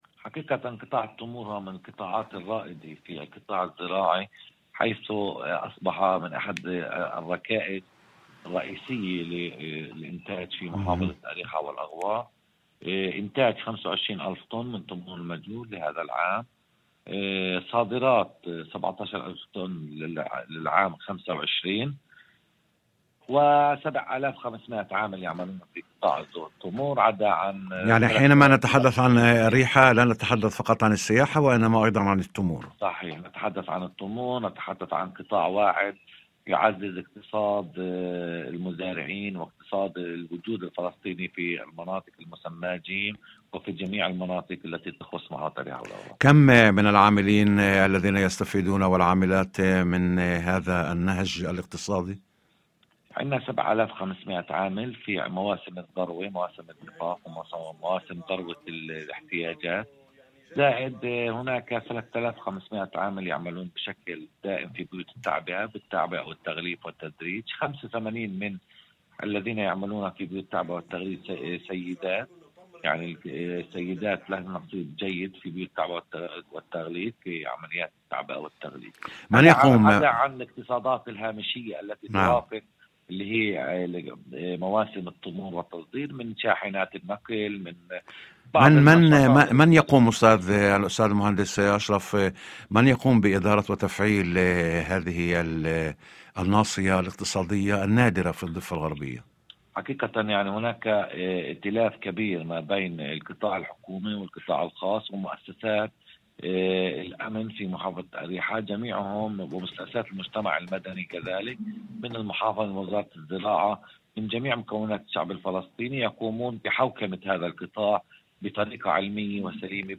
وأضاف في مداخلة هاتفية ضمن برنامج "يوم جديد"، على إذاعة الشمس، أن التمور أصبحت إحدى الركائز الرئيسية للإنتاج الزراعي في محافظة أريحا والأغوار، موضحاً أن صادرات العام الماضي بلغت 17 ألف طن.